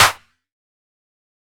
• Reverb Clap One Shot B Key 03.wav
Royality free clap one shot - kick tuned to the B note. Loudest frequency: 3321Hz
reverb-clap-one-shot-b-key-03-SCN.wav